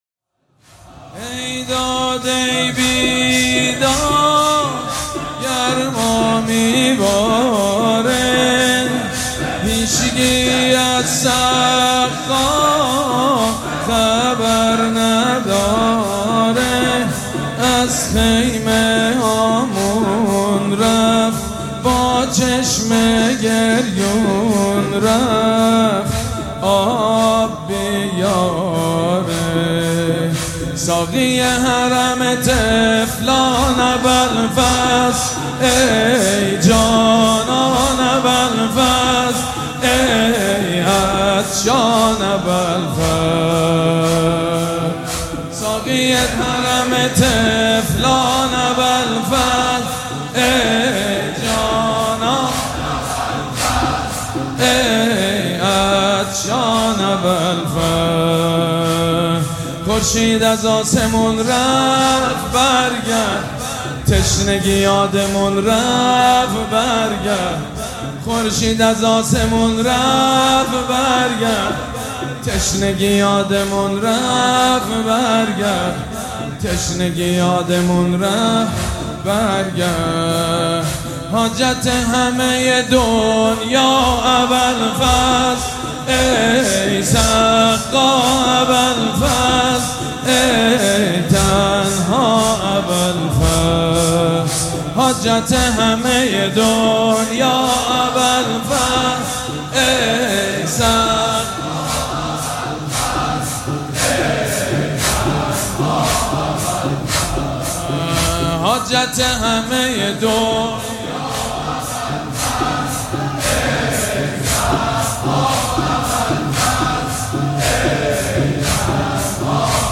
روضه و مرثیه ها